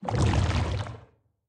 Sfx_creature_bruteshark_swim_slow_04.ogg